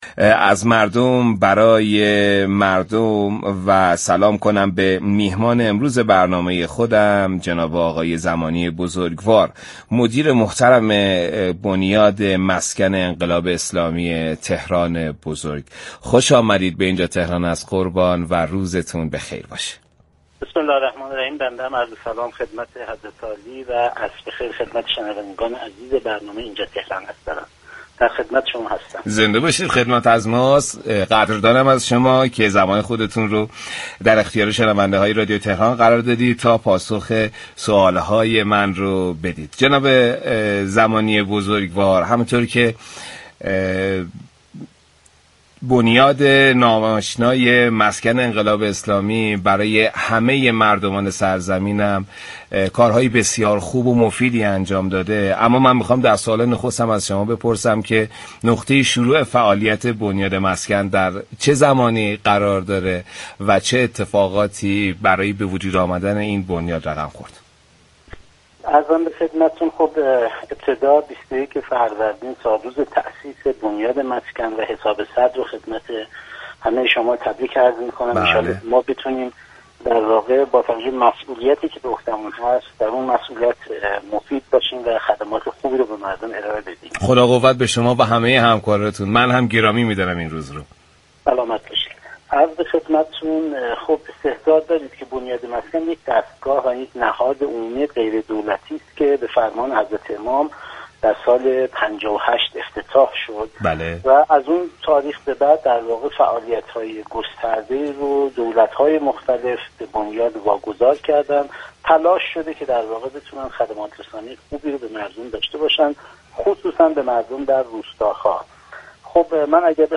در گفت و گو با «اینجا تهران است»